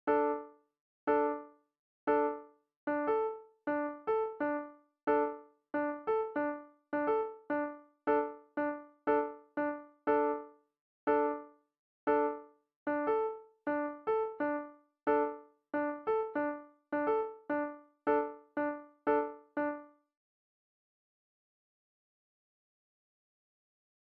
The beat does double time.